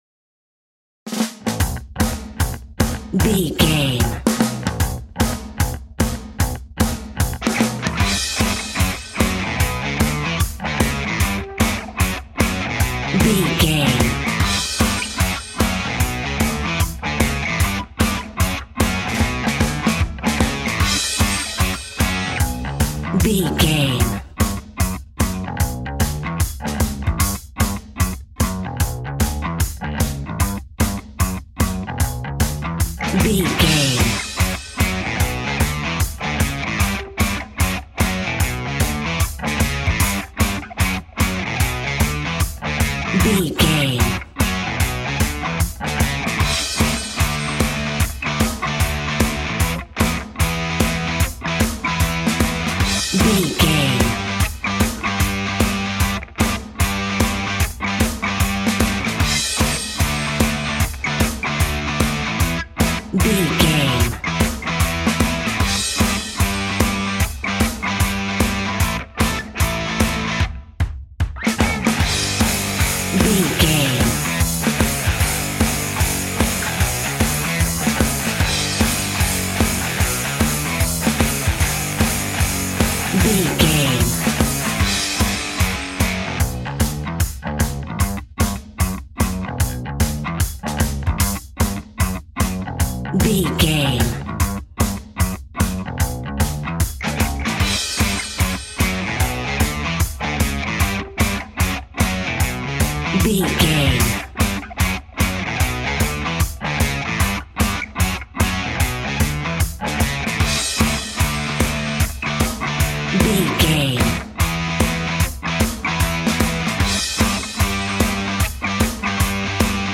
Ionian/Major
fun
energetic
uplifting
instrumentals
indie pop rock music
upbeat
groovy
guitars
bass
drums
piano
organ